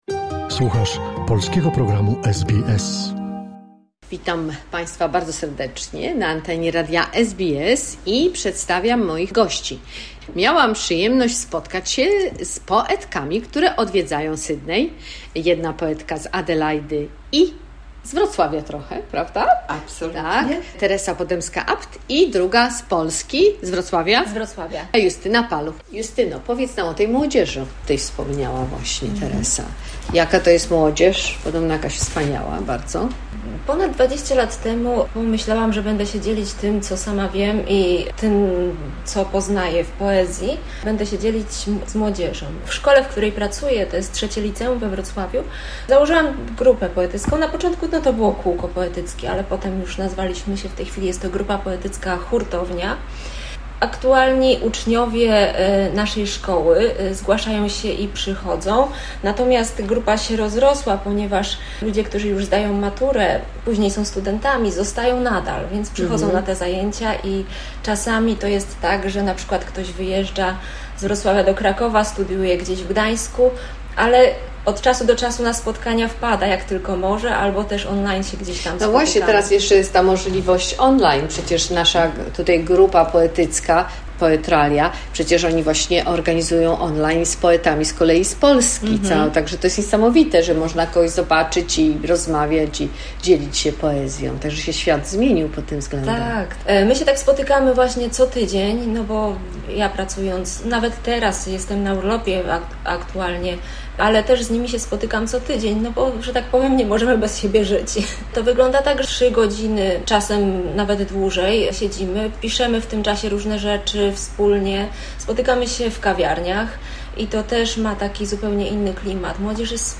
Druga część rozmowy dwóch poetek